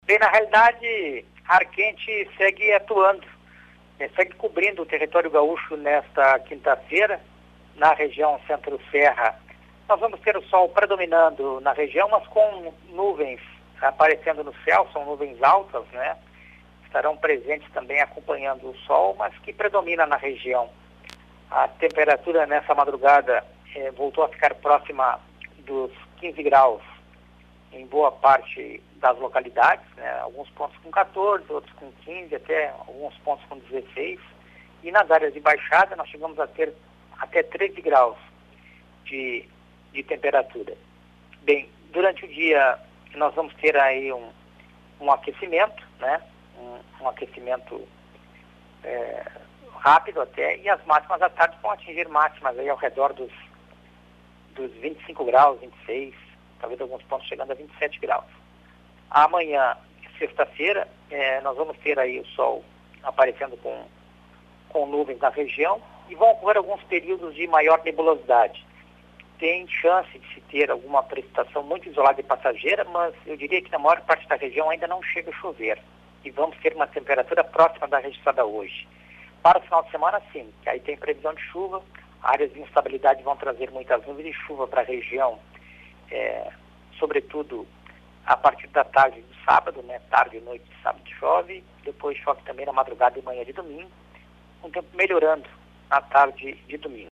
previsão do tempo para o Centro Serra